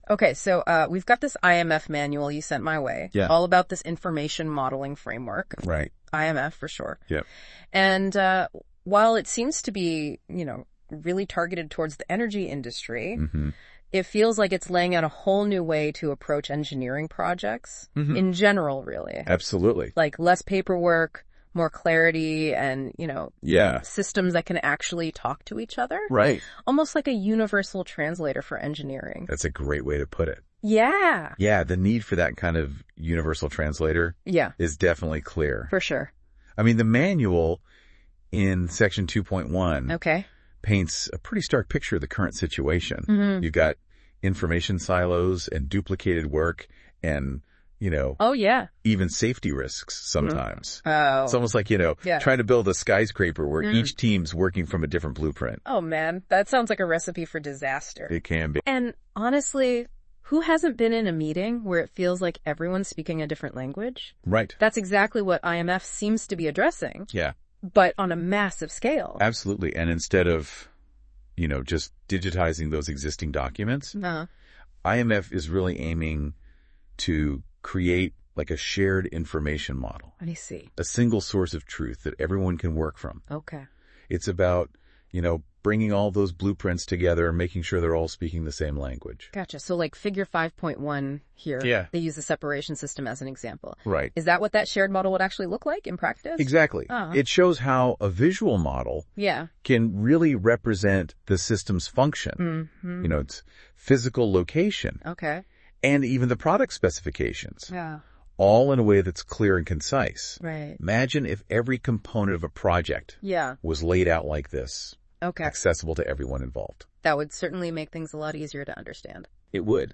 Using Google NotebookLM, we have created a podcast that simplifies the IMF Manual (Version 0.3.0) into an easy to follow discussion. The podcast features a conversation between two voices, a male and a female, who go through the key points.